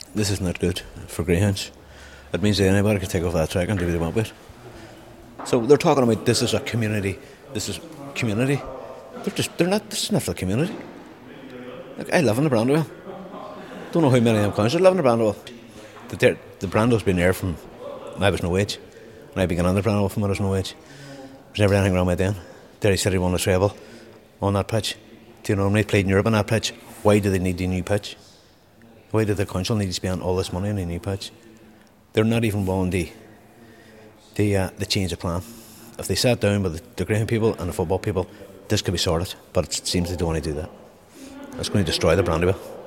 our reporter
greyhound owner